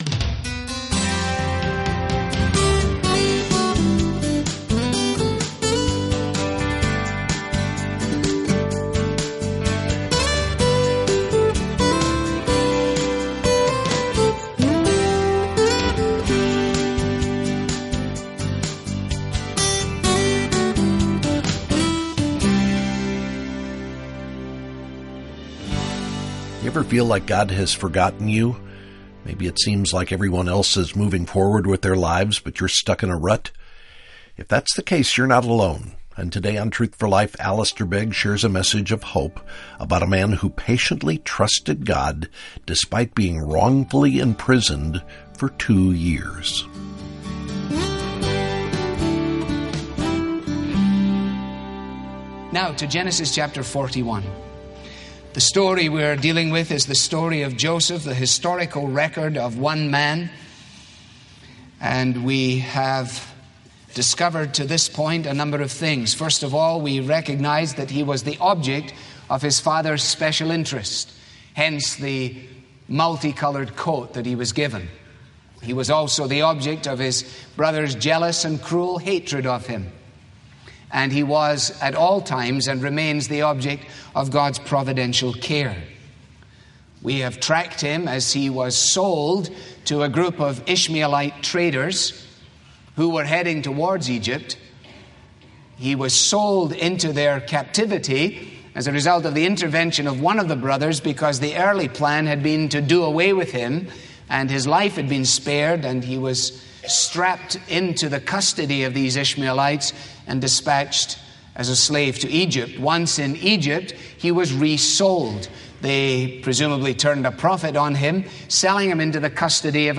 • This program is part of the sermon ‘The Hand of God, Volume 1’ • Learn more about our current resource, request your copy with a donation of any amount.